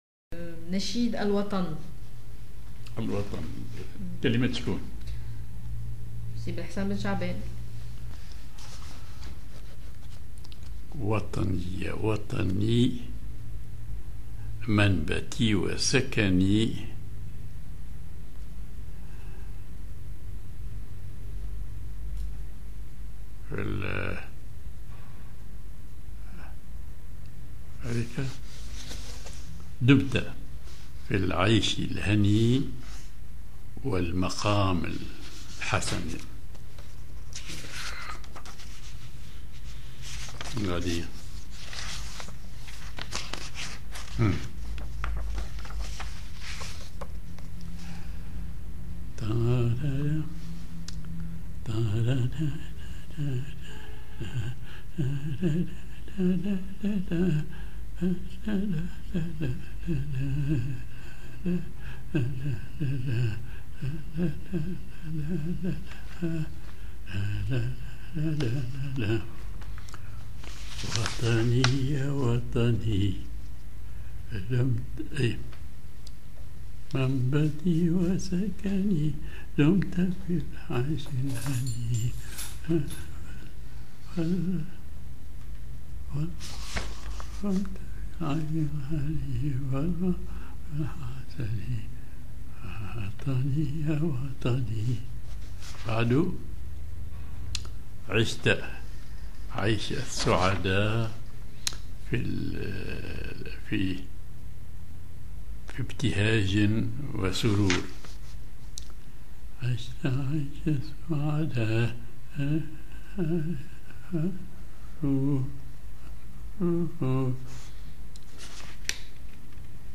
genre نشيد